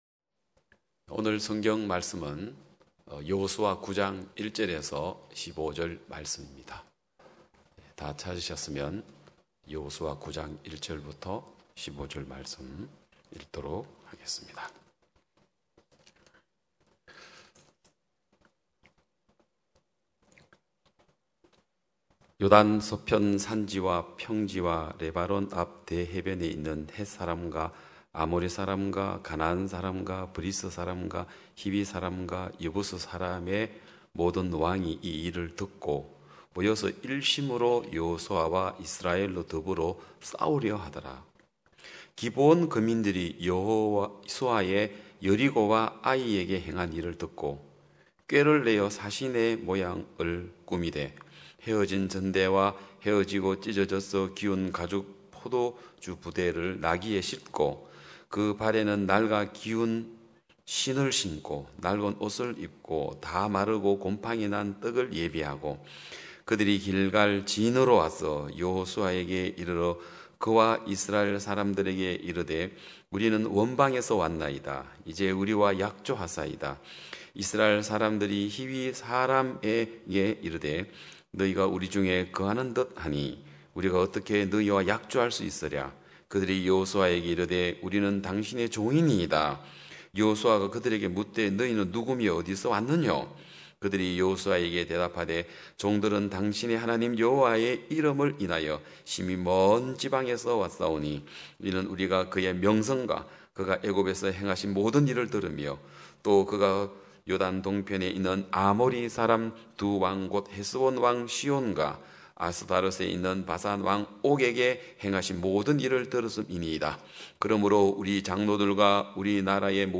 2021년 9월 5일 기쁜소식양천교회 주일오전예배
성도들이 모두 교회에 모여 말씀을 듣는 주일 예배의 설교는, 한 주간 우리 마음을 채웠던 생각을 내려두고 하나님의 말씀으로 가득 채우는 시간입니다.